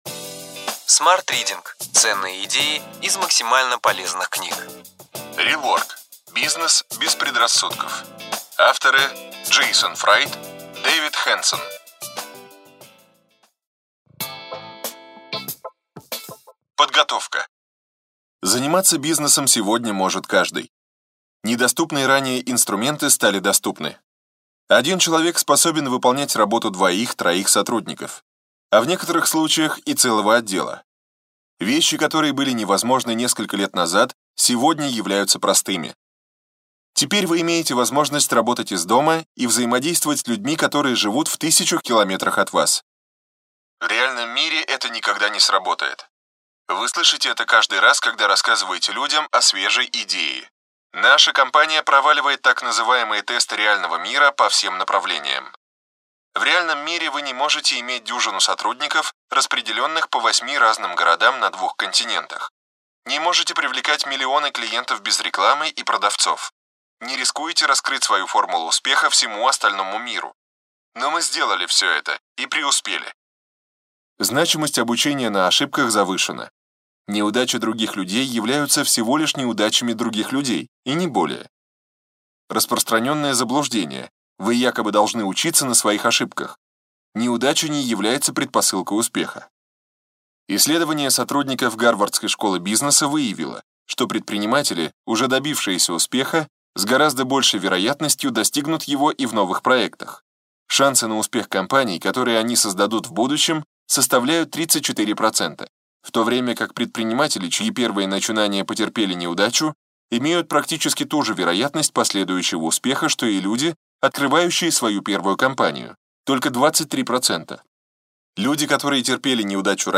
Аудиокнига Ключевые идеи книги: Rework.